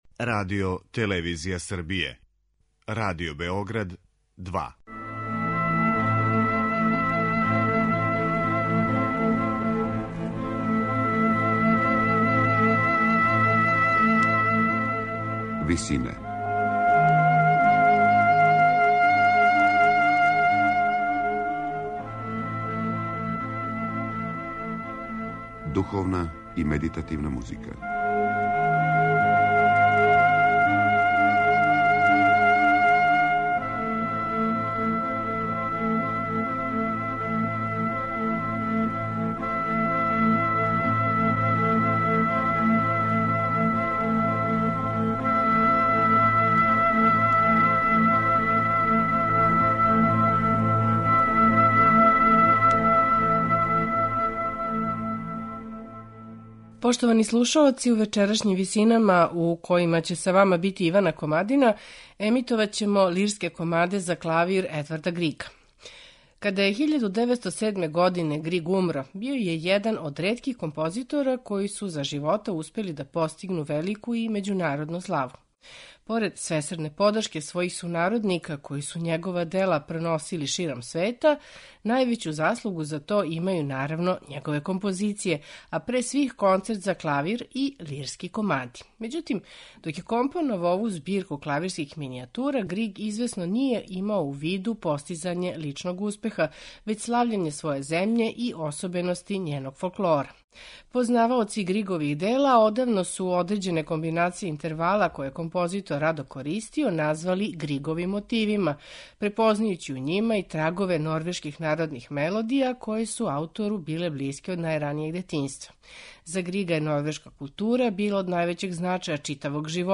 Међутим, док је током готово четири деценије компоновао ову збирку клавирских минијатура, Григ извесно није имао у виду постизање личног успеха, већ слављење своје земље и особености њеног фолклора.
У вечерашњим Висинама Григове "Лирске комаде" слушаћемо у интерпретацији пијанисте Хавијера Перијанеса.